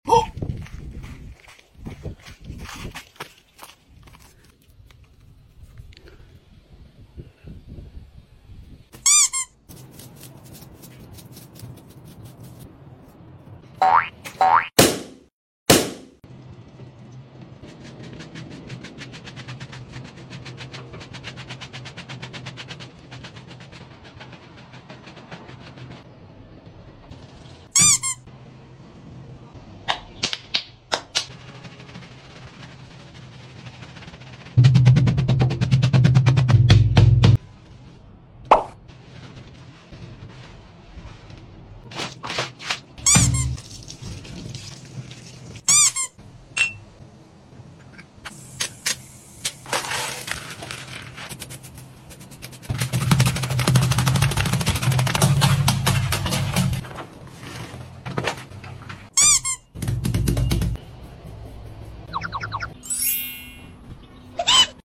toy sound effects free download